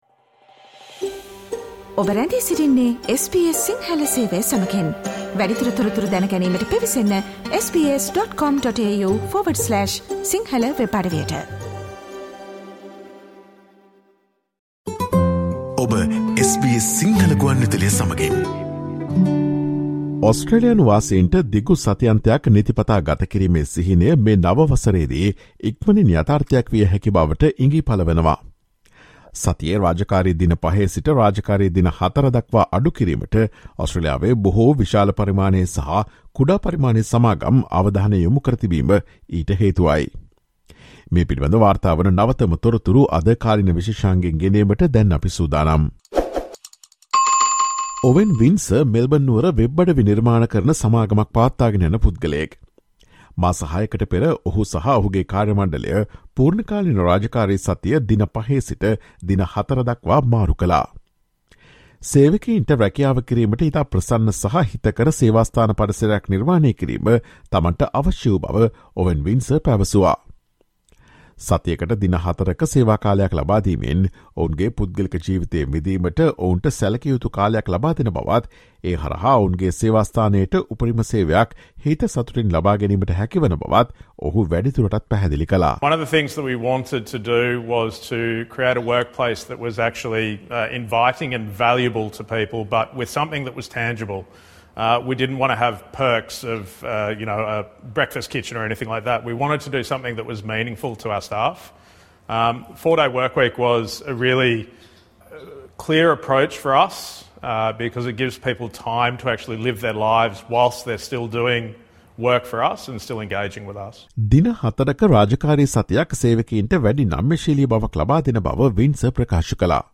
More Australian companies - big and small are making the change with the hopes it will improve staff's work and life balance. Listen to the SBS Sinhala Radio's current affairs feature on Thursday, 12 January.